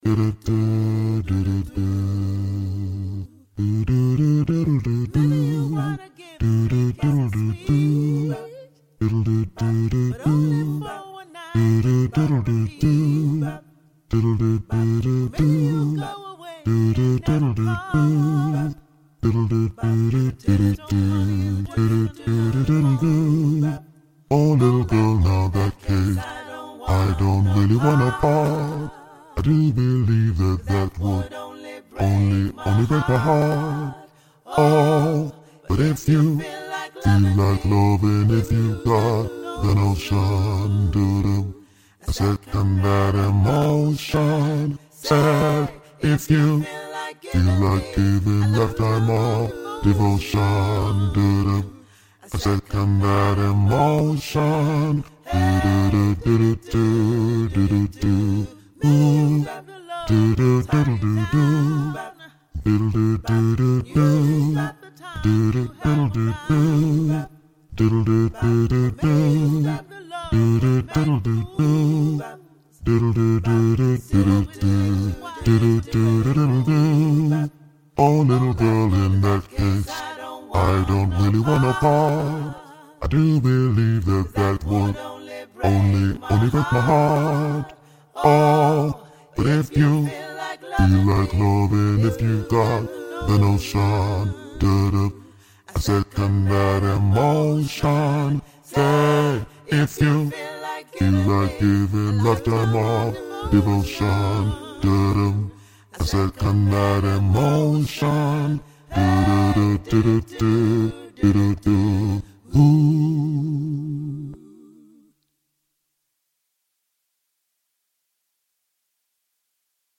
Genre: Choral.
12-I-Second-that-Emotion-Bass-louder.mp3